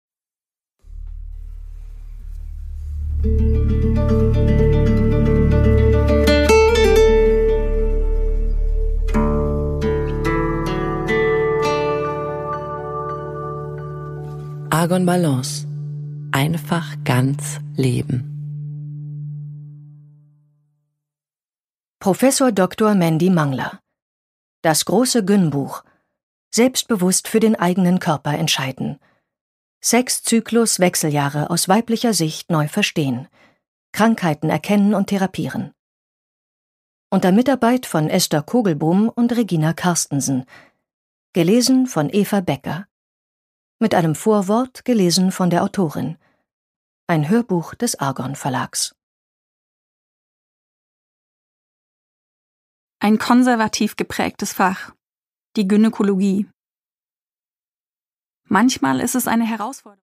Produkttyp: Hörbuch-Download
Mit einem Vorwort gelesen von der Autorin.